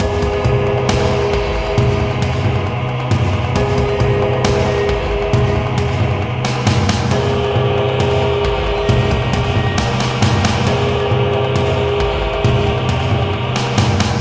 (gamerip)